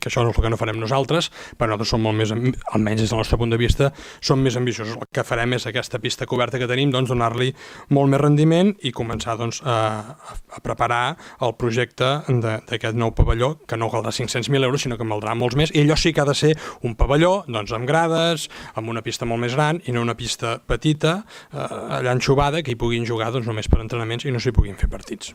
L’alcalde Marc Buch va respondre al portaveu republicà assenyalant quines són les prioritats de l’executiu local: